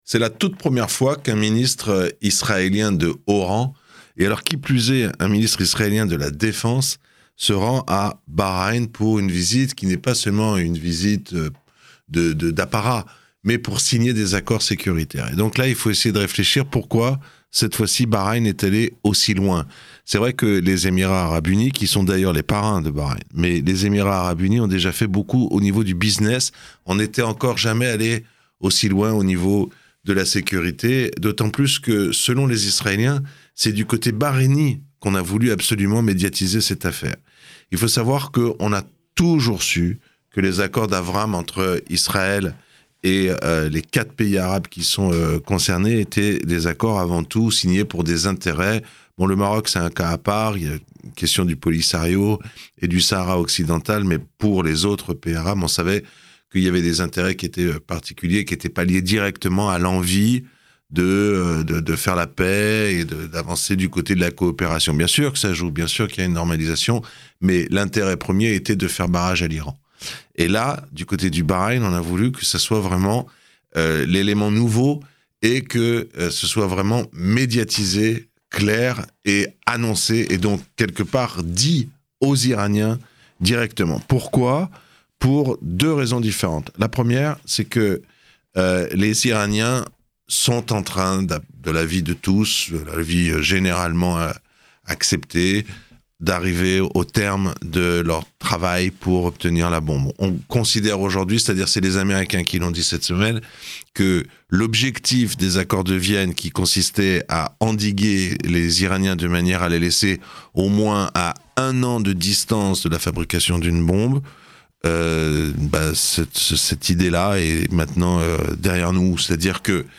Israël signe un accord de défense avec Bahreïn, une première. Analyse